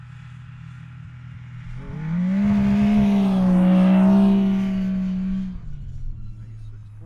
Snowmobile Description Form (PDF)
Subjective Noise Event Audio File (WAV)